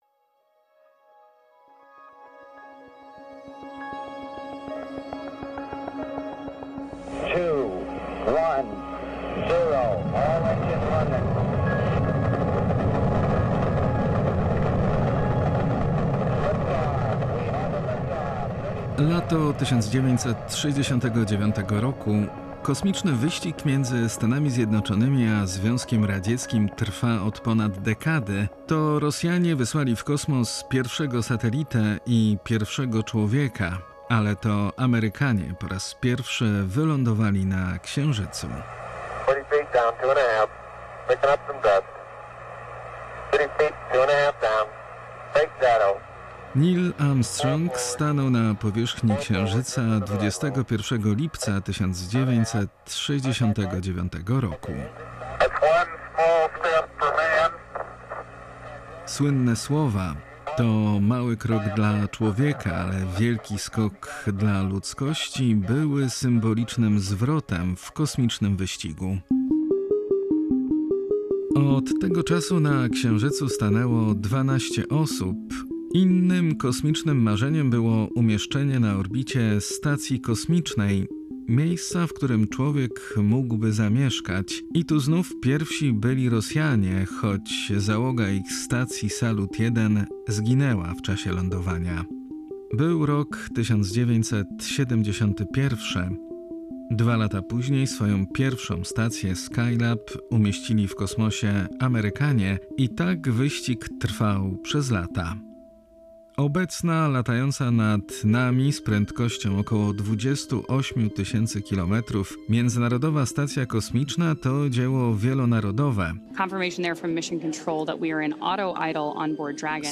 W reportażu wykorzystano archiwalne fragmenty Audycji Wyborczych „Solidarności”